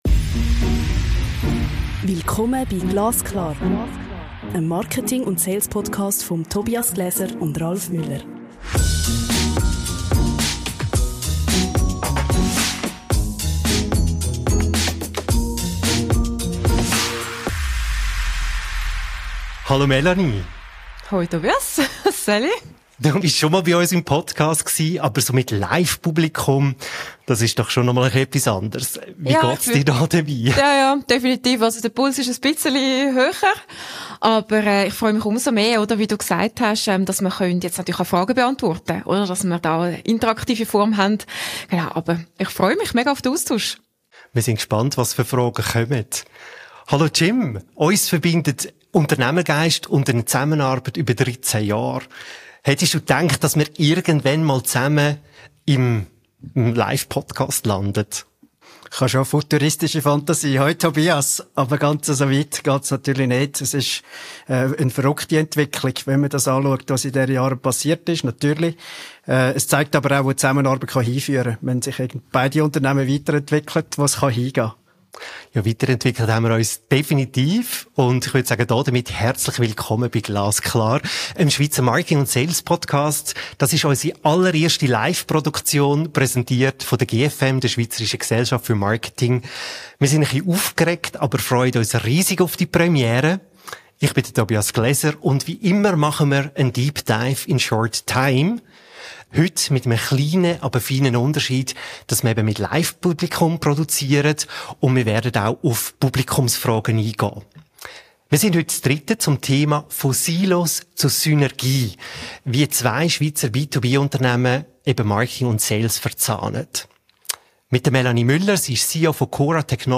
Premiere bei GLASKLAR: Unser erster Live-Podcast präsentiert von gfm.